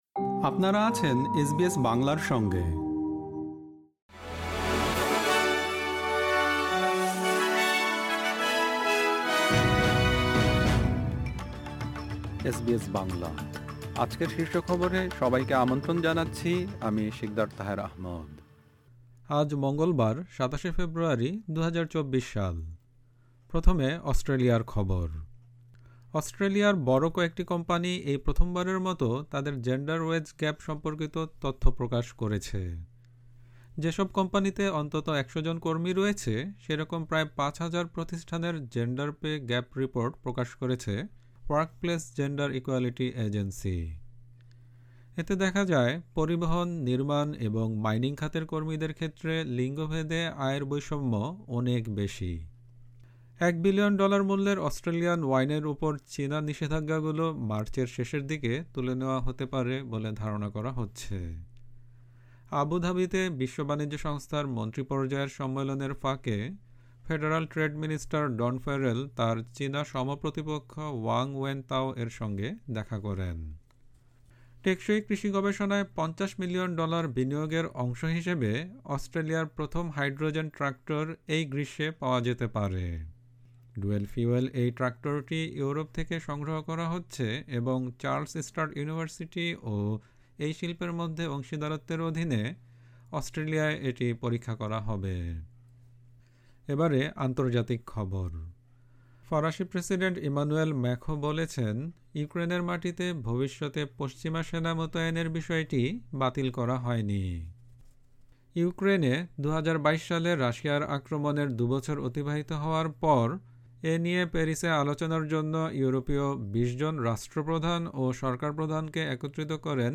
এসবিএস বাংলা শীর্ষ খবর: ২৭ ফেব্রুয়ারি, ২০২৪